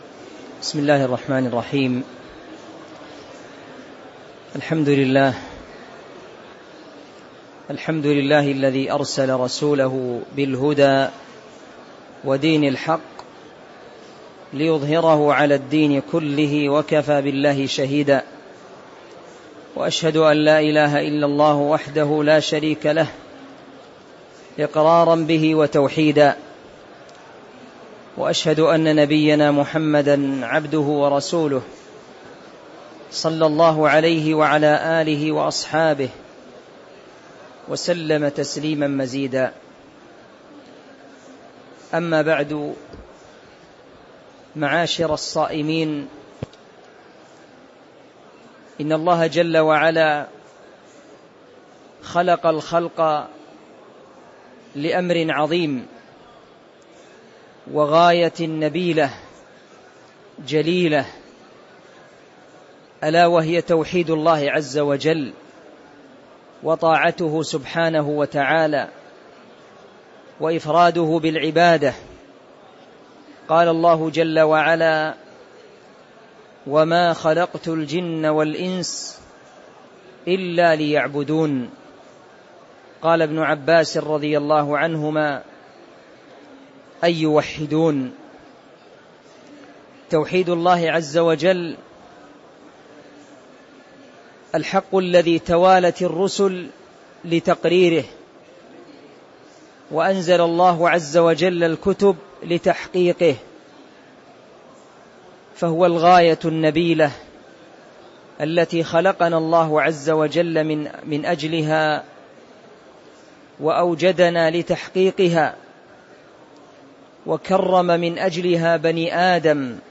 تاريخ النشر ٢١ رمضان ١٤٤٤ هـ المكان: المسجد النبوي الشيخ